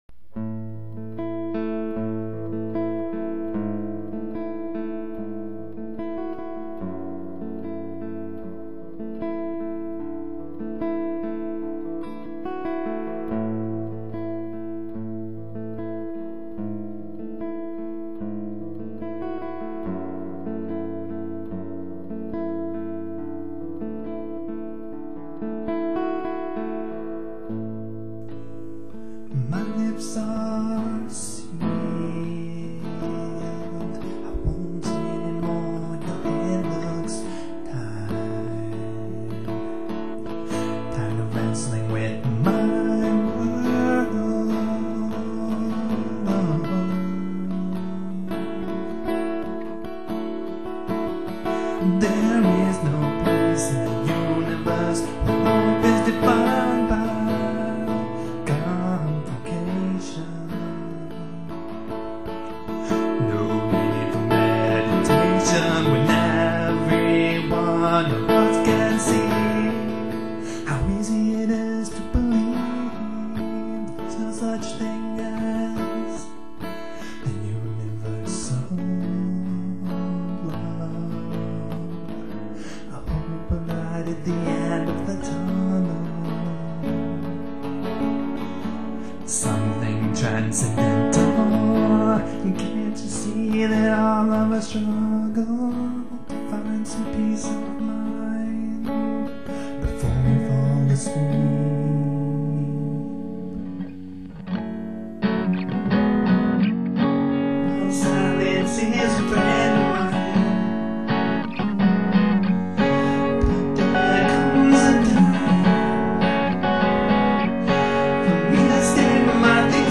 All songs recorded in May 2002 in Dallas, Texas